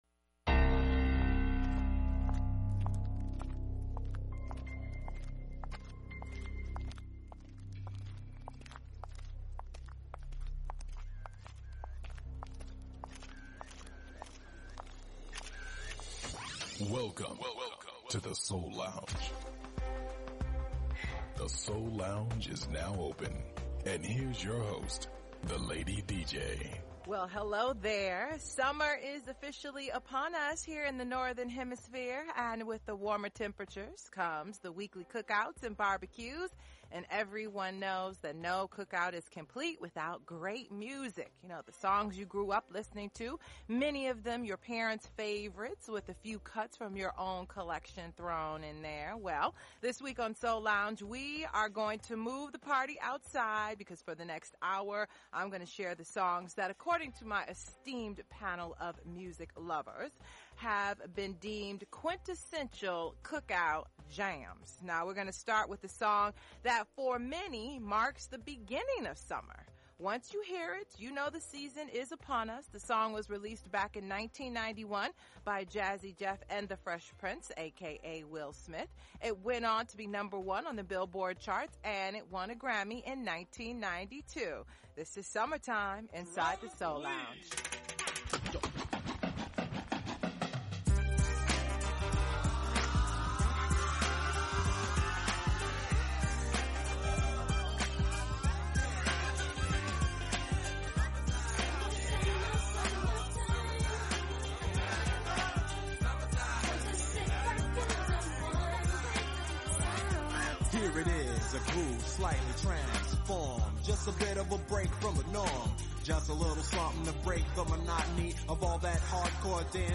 music, interviews and performances
Neo-Soul
conscious Hip-Hop
Classic Soul